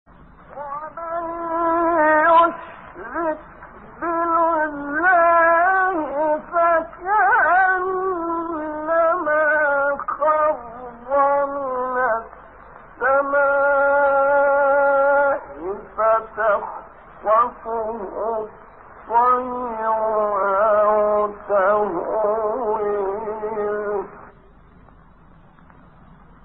6 فراز صوتی در مقام «کُرد»
گروه شبکه اجتماعی: فرازهایی صوتی از تلاوت شش قاری برجسته مصری که در مقام کُرد اجرا شده‌اند، ارائه می‌شود.